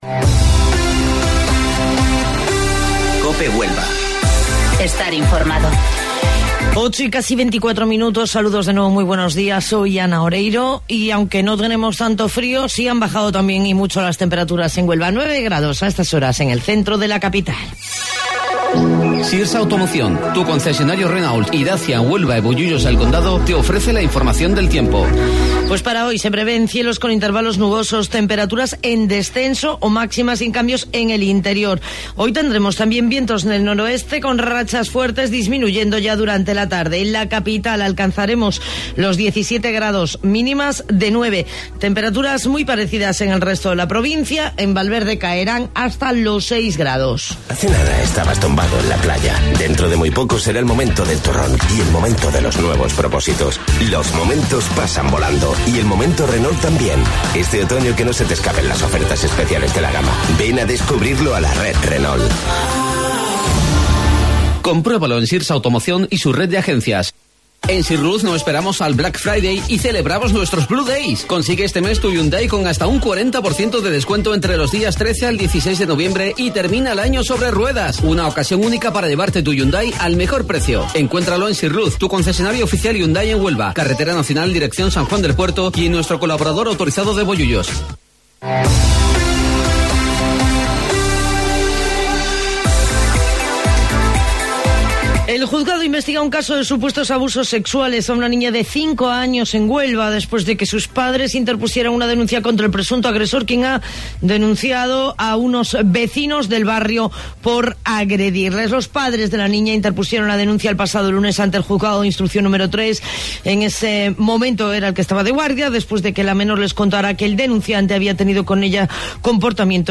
AUDIO: Informativo Local 08:25 del 15 de Noviembre